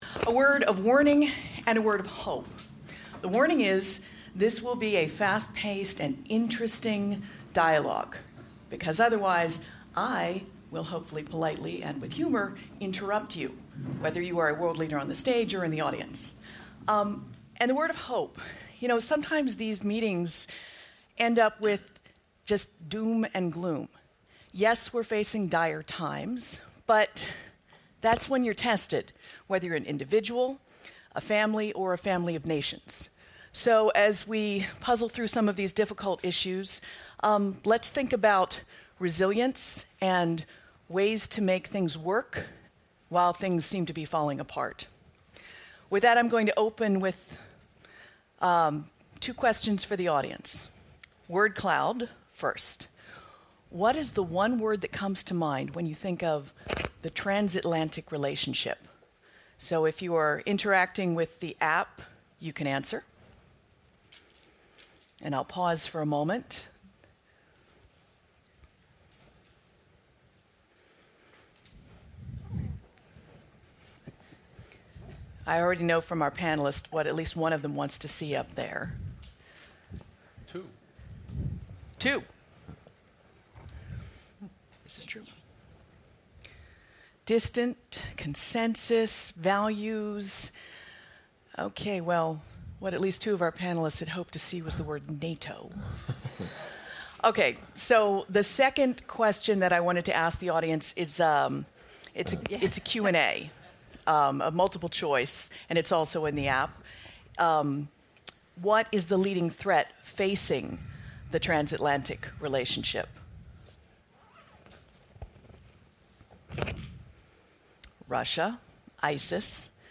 A Grave New World: Future Global security Challenges - Panel discussion with NATO Secretary General Jens Stoltenberg at the Brussels Forum organized by the German Marshall Fund of the United States